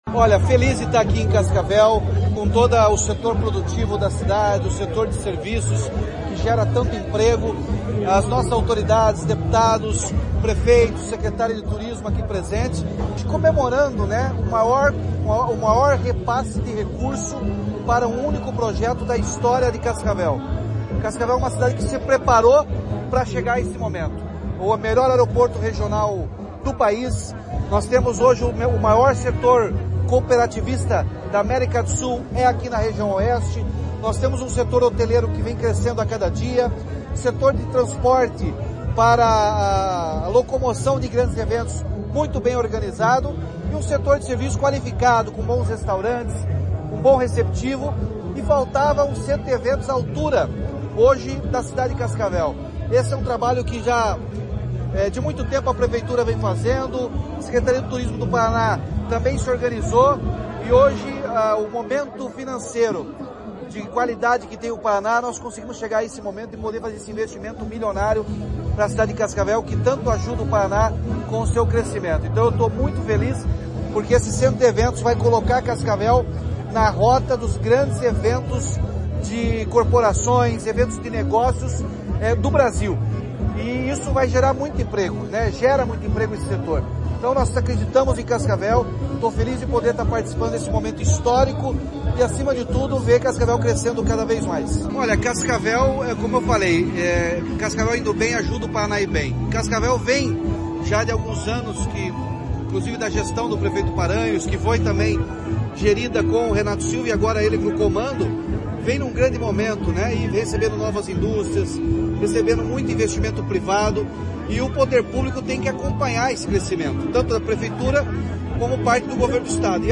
Sonora do governador Ratinho Júnior sobre a construção do centro de convenções e eventos do Oeste